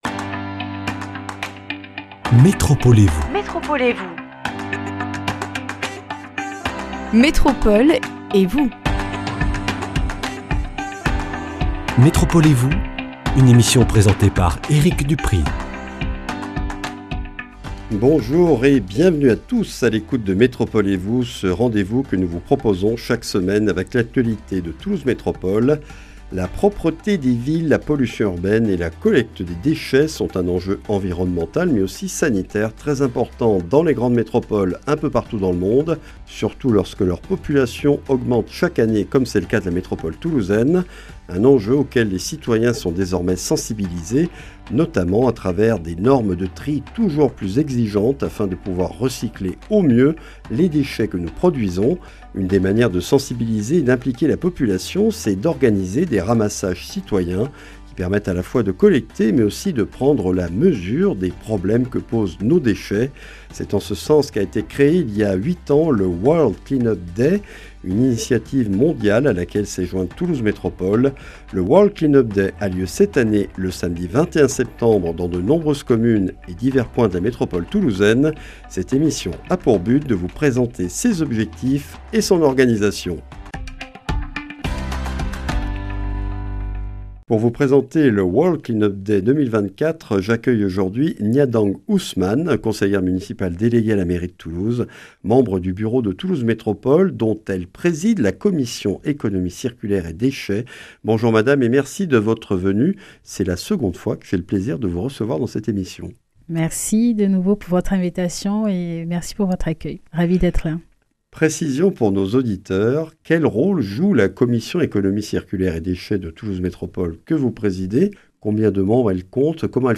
Le World Clean Up Day, journée mondiale du nettoyage, a lieu cette année le samedi 21 septembre dans la métropole toulousaine. Vingt-et-une communes dont Toulouse y participent autour de points d’accueil et de ramassage sur leur territoire. Présentation de l’événement avec Gnadang Ousmane, présidente de la commission Économie circulaire de Toulouse Métropole.